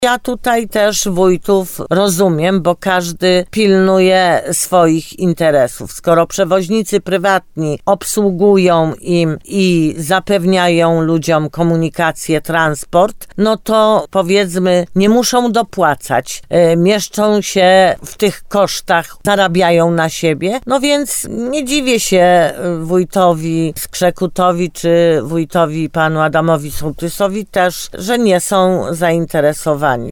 Jak mówiła w programie Słowo za Słowo w radiu RDN Nowy Sącz burmistrz Limanowej Jolanta Juszkiewicz, zależy jej na rozwoju komunikacji, ale rozumie też kalkulacje finansowe włodarzy wspomnianych samorządów.